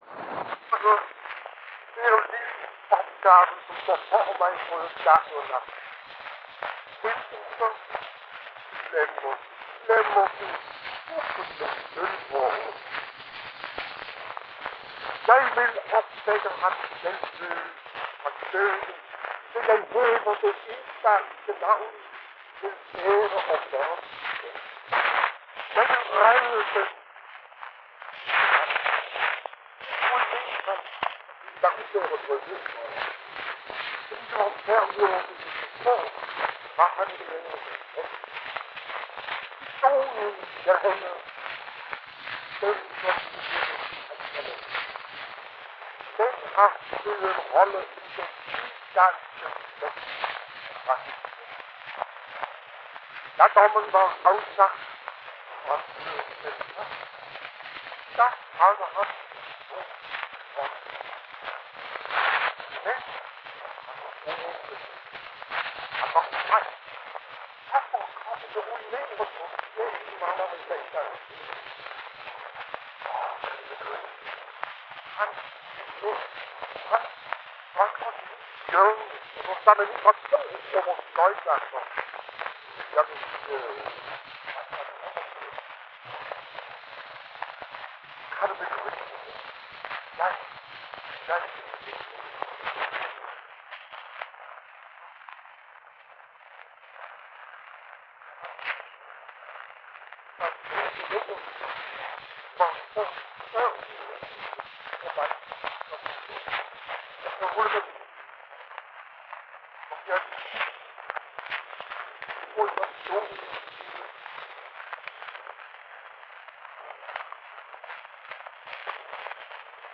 Uddrag af monologiseret dialog ved udeladelse af de fleste af Gregers' replikker. Om Emil Poulsen også taler Gregers få korte replikker er uvist. En meget ujævn optagelse med meget støj, der får uddraget til at fremstå som fragment.
Rubenvalse